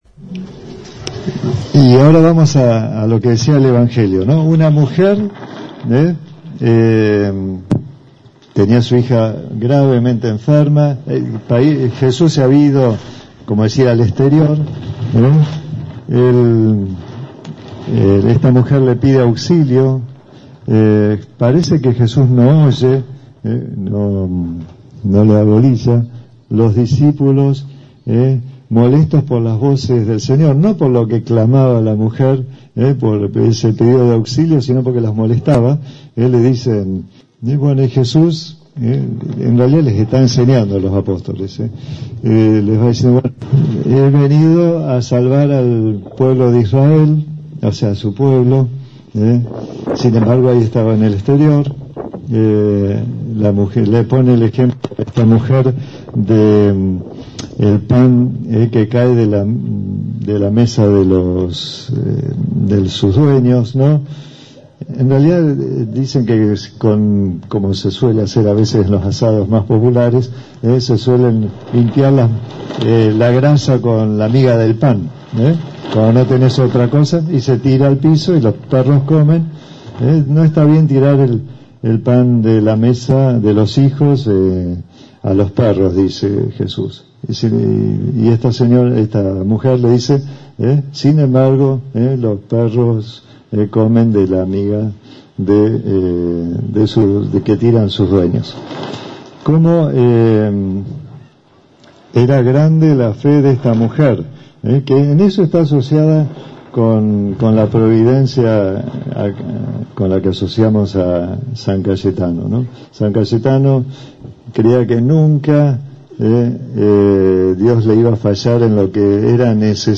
En su homilía, el sacerdote resaltó la labor del patrono del pan y del trabajo.